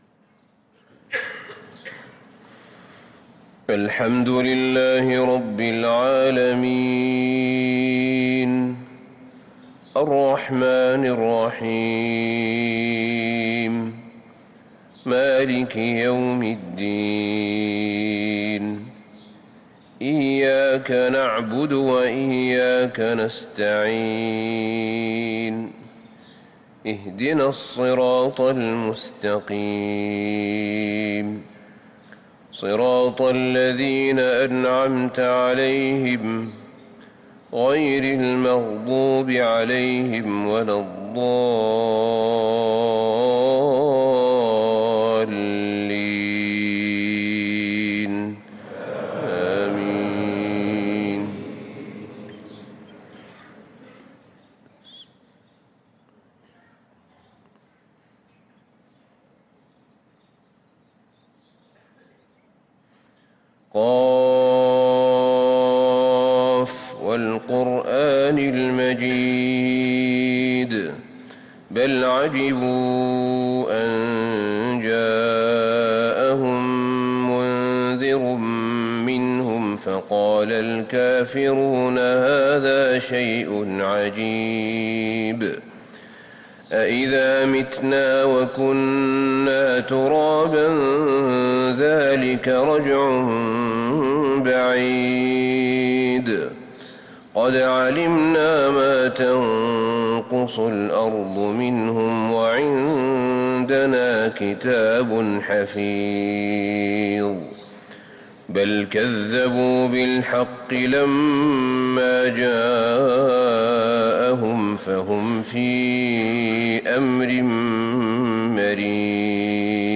صلاة الفجر للقارئ أحمد بن طالب حميد 16 ربيع الأول 1445 هـ